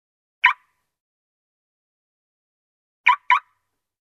Звуки брелка сигнализации
Ставим на охрану и потом снимаем